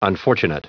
Prononciation du mot unfortunate en anglais (fichier audio)
Prononciation du mot : unfortunate